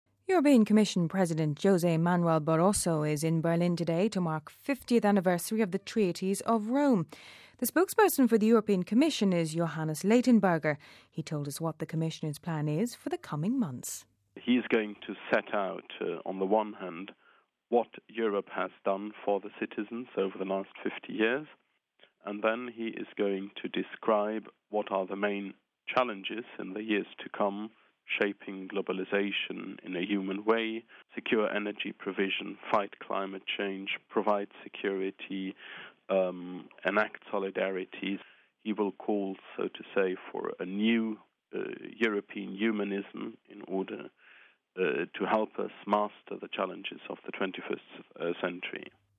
Home Archivio 2007-02-28 18:41:07 European Union Prepares to Mark 50th Anniversary (28 Feb 07 - RV) The EU is planning commemorations over the year to mark the 50th anniversary of the signing of the Treaty of Rome. We have this report....